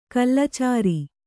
♪ kallacāri